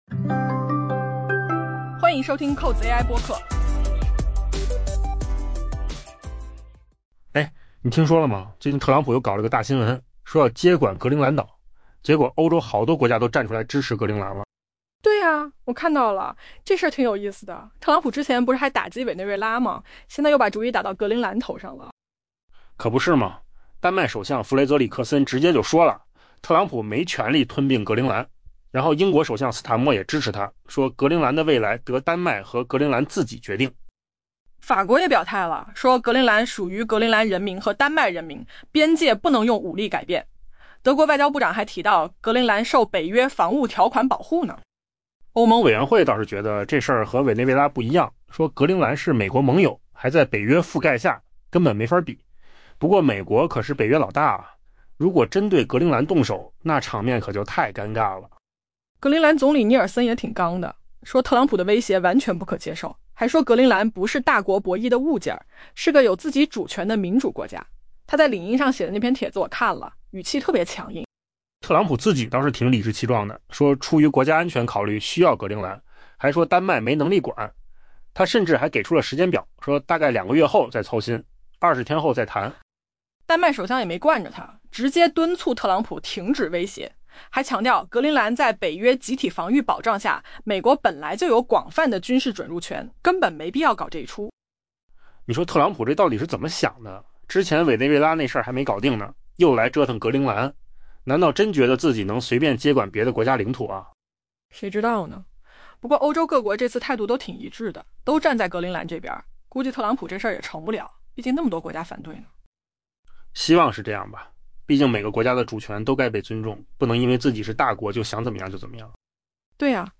AI 播客：换个方式听新闻 下载 mp3 音频由扣子空间生成 在美国总统特朗普继打击委内瑞拉之后，重新启动旨在接管格陵兰岛这一自治领土的行动之际，欧洲各国官员承诺将全力支持格陵兰。